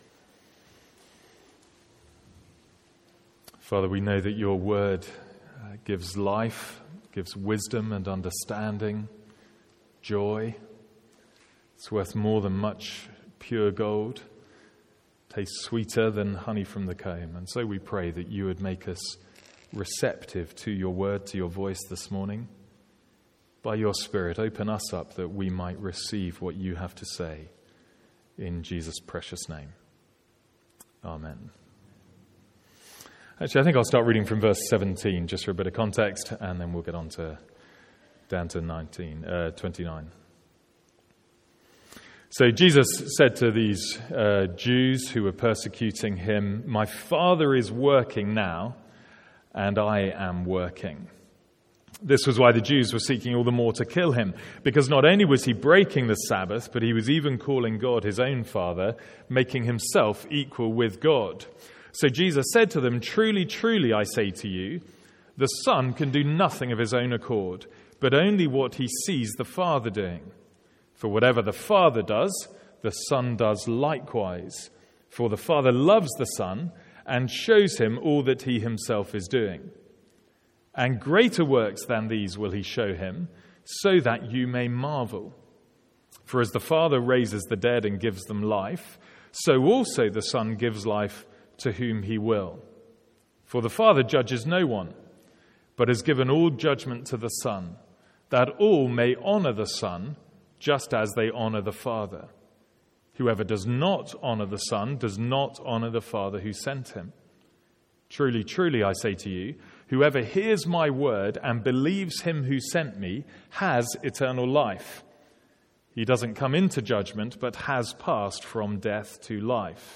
From our morning service in John's Gospel.